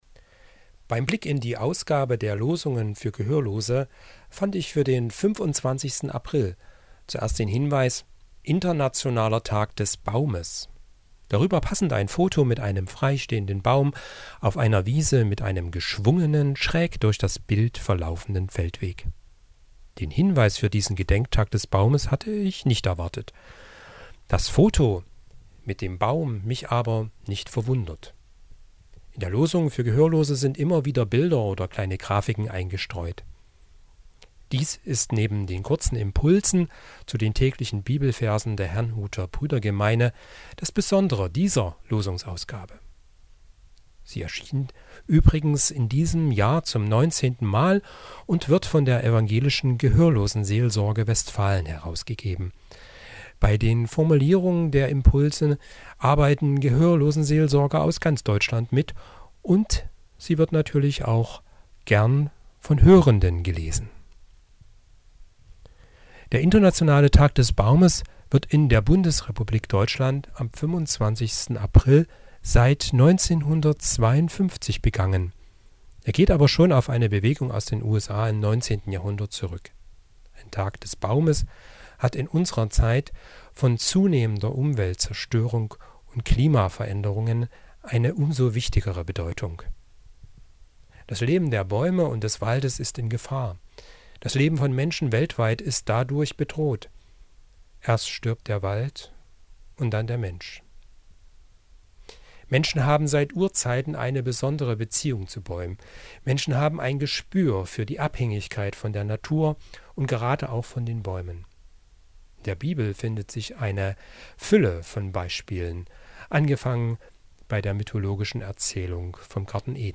Andachten zum Hören und Lesen von Mitarbeitenden der Evangelischen Landeskirche Anhalts